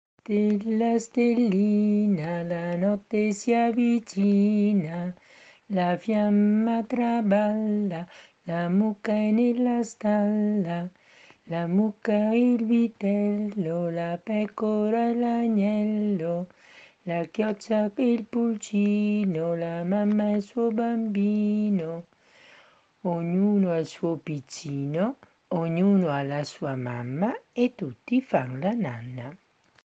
with background music!